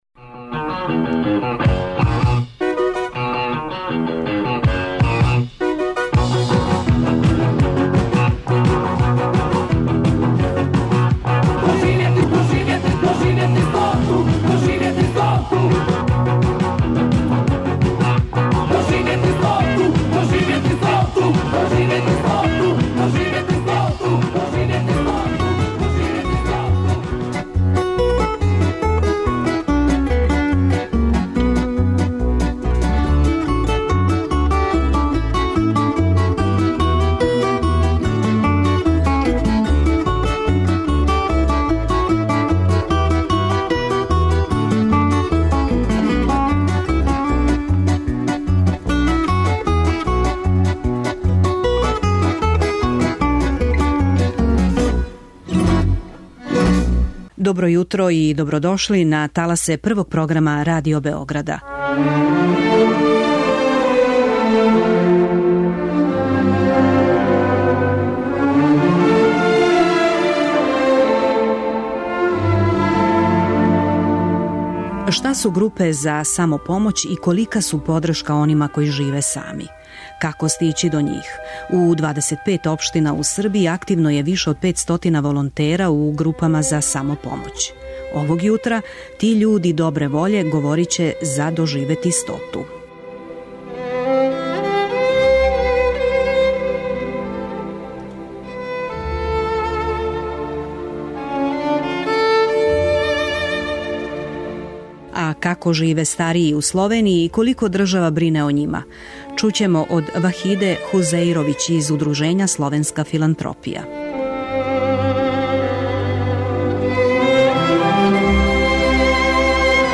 У 25 општина у Србији активно је више од 500 волонтера у групама за самопомоћ. Овог јутра, ти људи добре воље, говоре о свом ентузијазму и жељи да пруже руку руци која тражи.